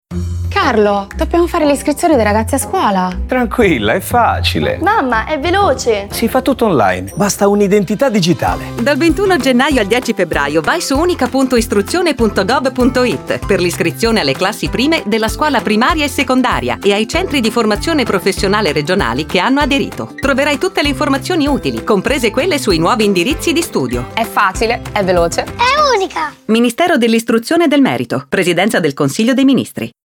Lo spot radio
mim-spot-30_-radio-v2.mp3